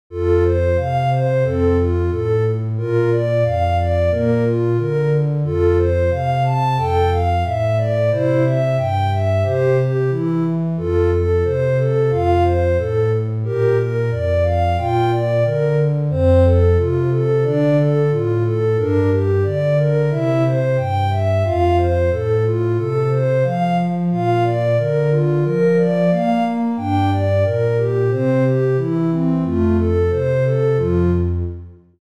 ゆったりした曲です。オルガンっぽい音。ループ対応。
BPM90 オルガン